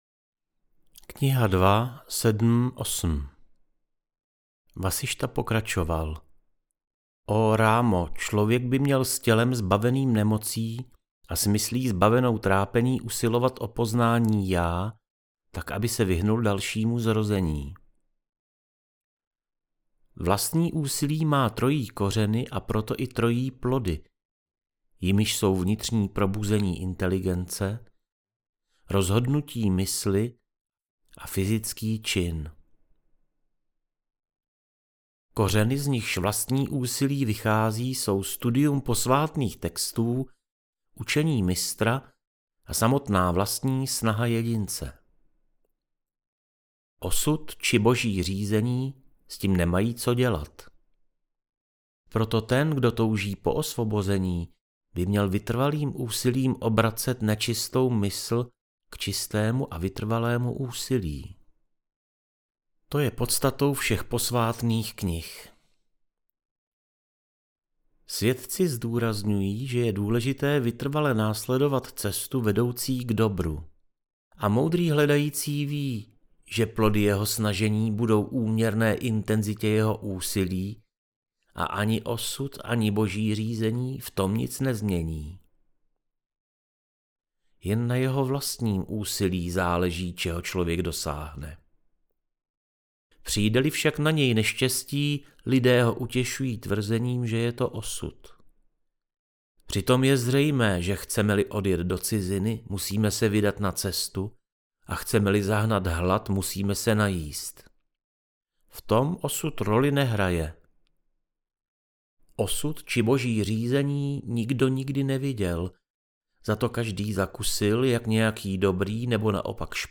JÓGA VÁSIŠTHA - AUDIOKNIHA